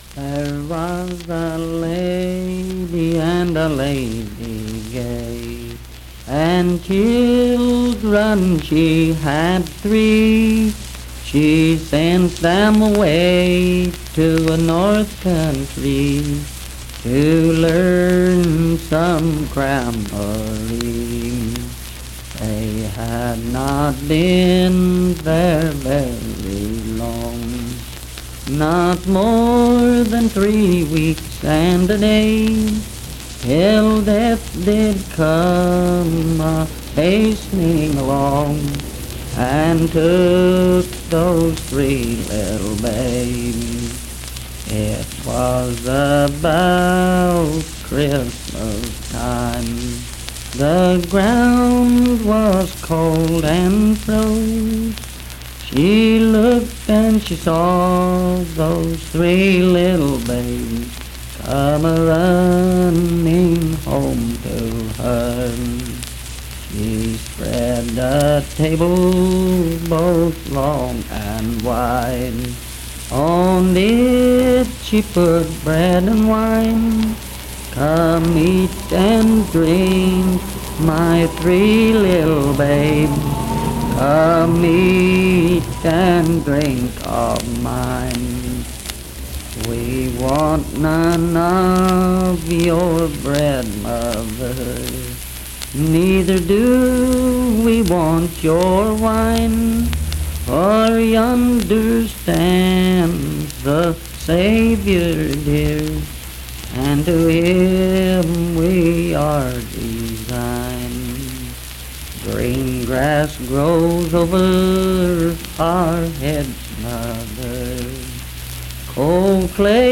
Unaccompanied vocal music performance
Voice (sung)
Wirt County (W. Va.)